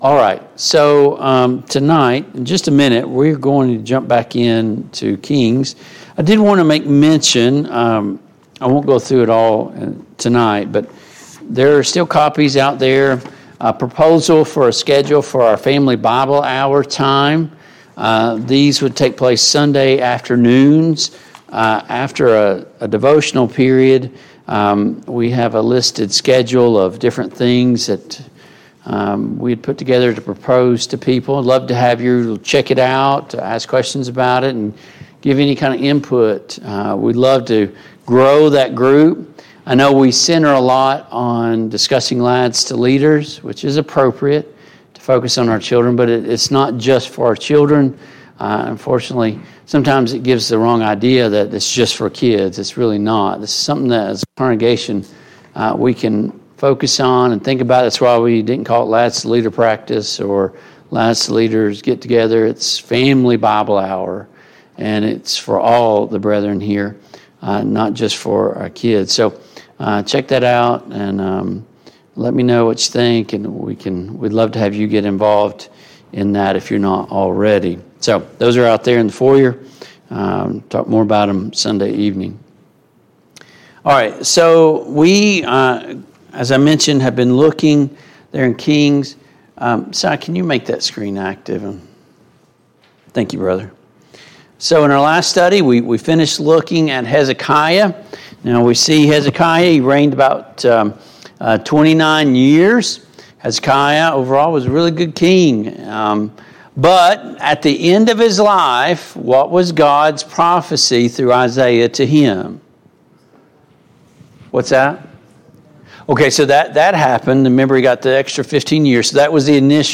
The Kings of Israel and Judah Service Type: Mid-Week Bible Study Download Files Notes « 76.